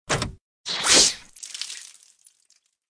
AA_squirt_flowersquirt_miss.ogg